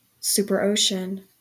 Ääntäminen
Ääntäminen US RP : IPA : /ˈsuːpəɹˌəʊ.ʃən/ GA : IPA : /ˈsuːpɚˌoʊ.ʃən/ Haettu sana löytyi näillä lähdekielillä: englanti Käännöksiä ei löytynyt valitulle kohdekielelle. Määritelmät Substantiivi An ocean surrounding a supercontinent .